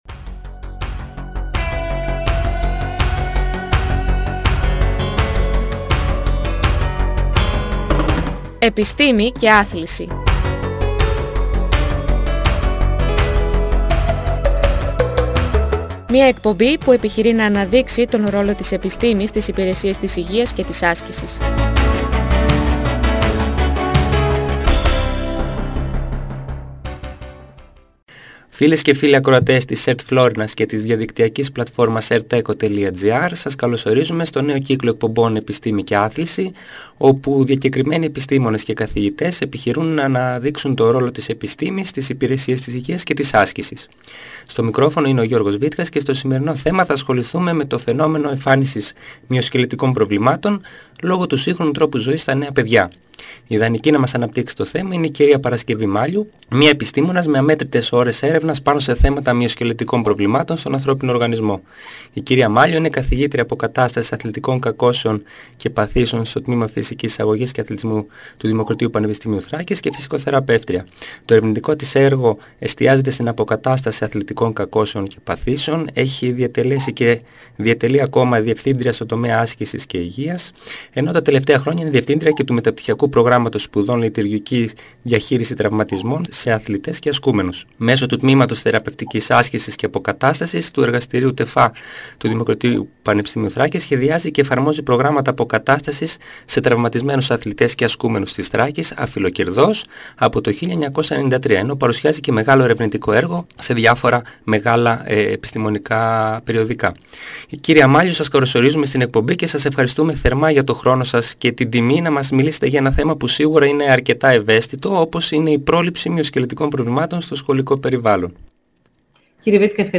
Επισυνάπτεται οδηγός για γονείς, δασκάλους και παιδιά, για προσπάθεια παρακίνησης των παιδιών στην άσκηση «Επιστήμη και Άθληση» Μια εκπαιδευτική εκπομπή όπου διακεκριμένοι καθηγητές και επιστήμονες, αναδεικνύουν τον ρόλο της επιστήμης στις υπηρεσίες της υγείας και της άσκησης.